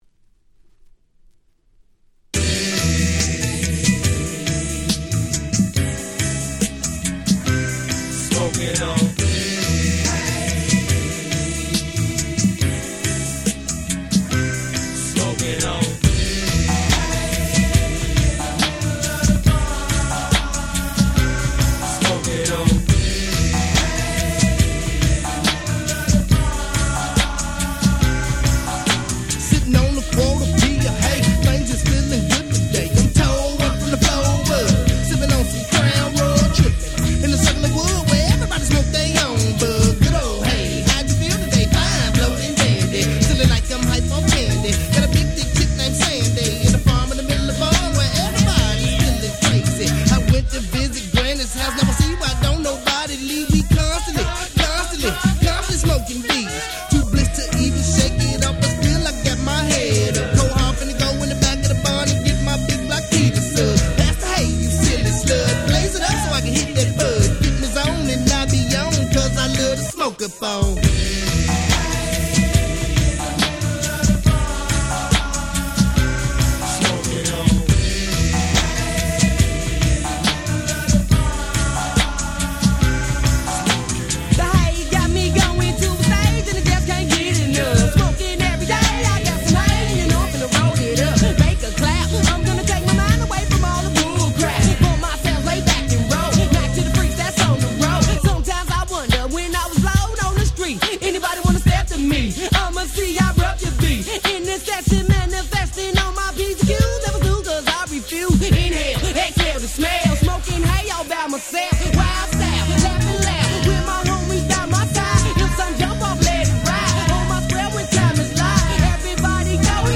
96' Big Hit West Coast Hip Hop !!
West Coast G-Rap Gangsta Rap